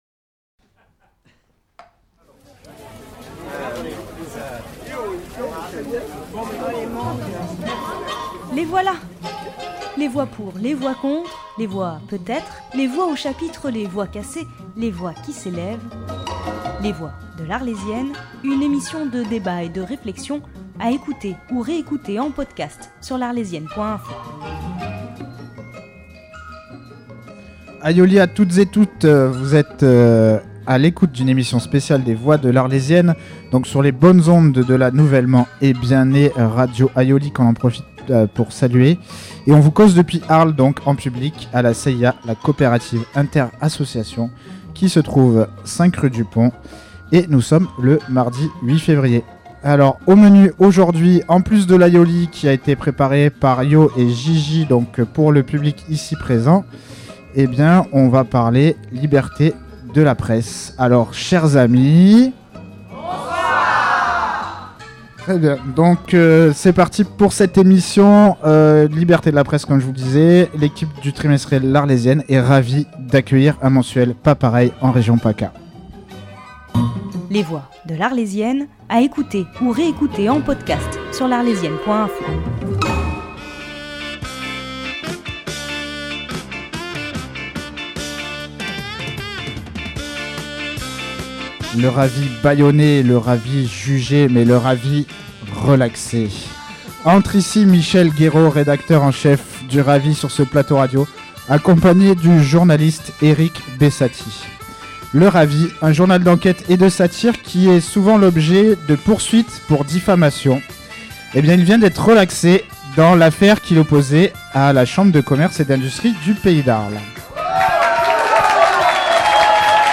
Retour sur l'affaire et le procès du Ravi vs la CCI d'Arles au cours d'une émission sur la liberté de la presse avec Radio Aïoli et l'Arlésienne.
Le soir du délibéré, une soirée était organisée à Arles avec les équipes de l’Arlésienne et du Ravi ainsi que Radio Aïoli.